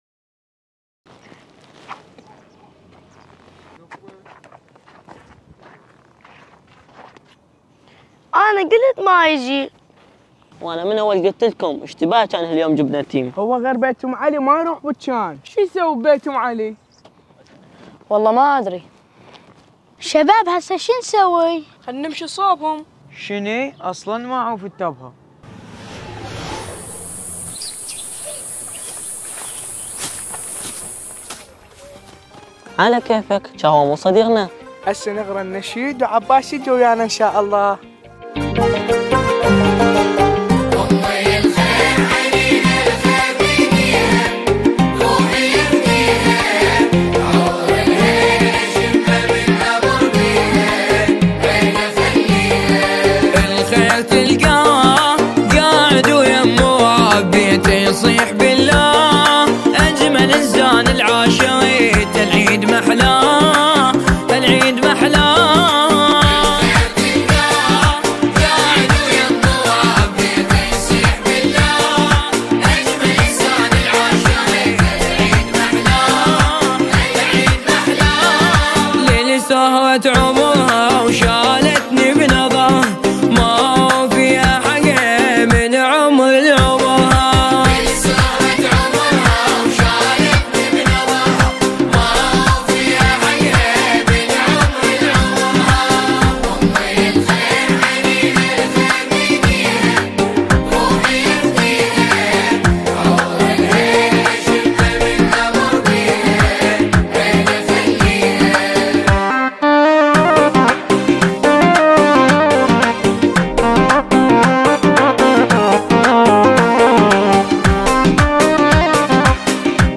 نماهنگ زیبای عربی - فارسی